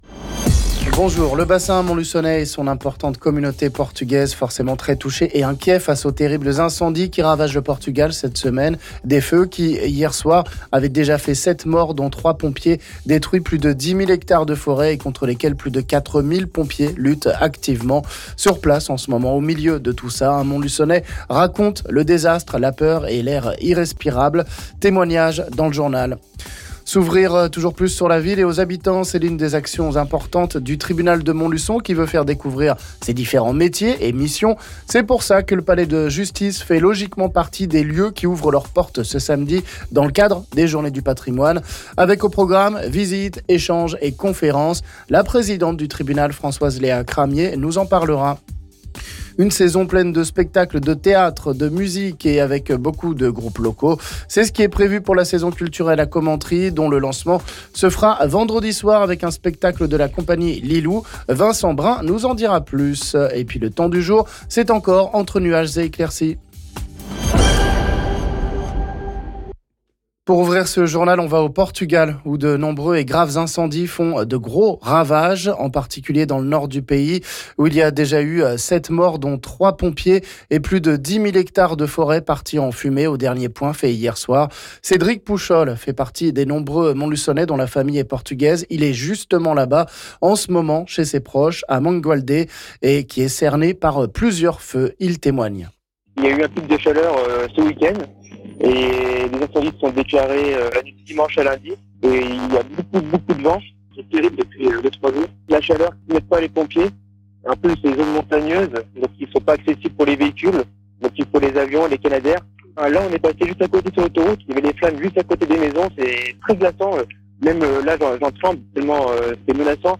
On en parle ici avec la présidente du tribunal judiciaire de Montluçon Françoise-Léa Cramier...